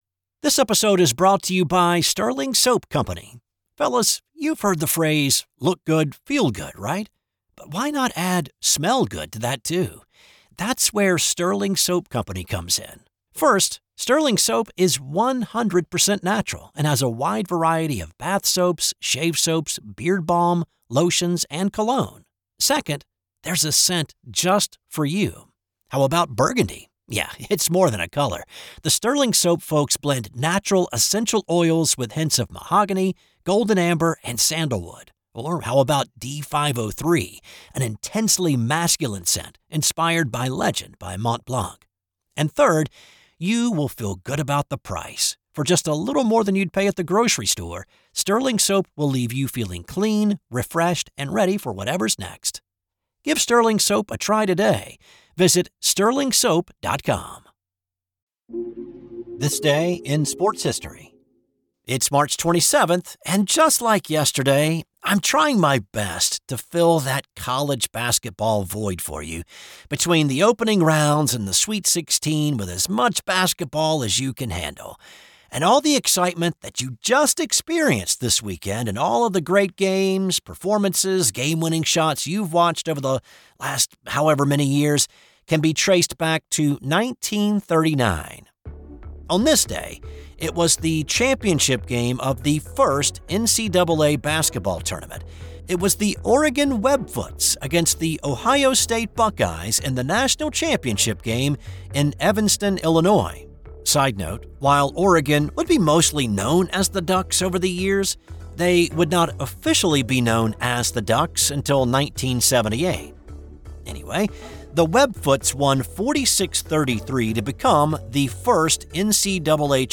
'This Day in Sports History' is a one PERSON operation.